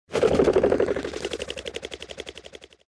Звуки фазана
На этой странице собраны разнообразные звуки фазанов – от характерного квохтания до резких тревожных криков.